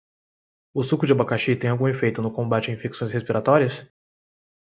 Pronounced as (IPA) /ˈsu.ku/